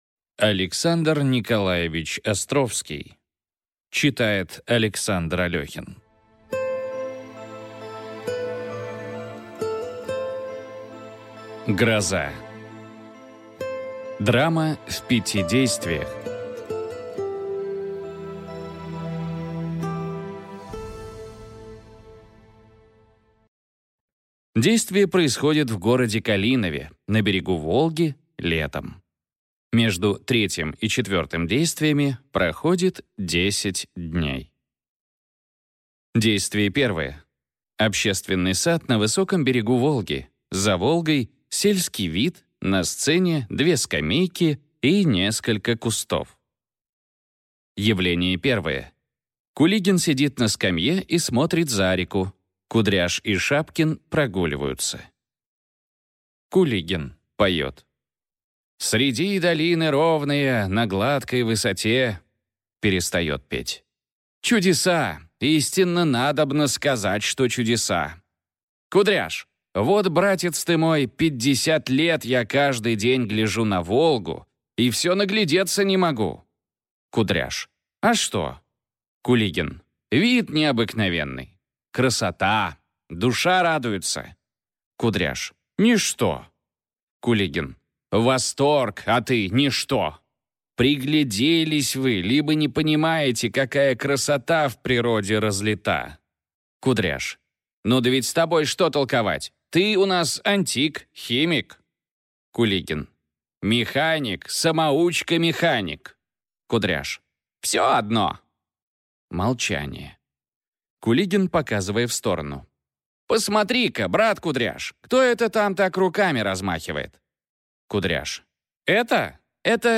Аудиокнига Гроза. Бесприданница | Библиотека аудиокниг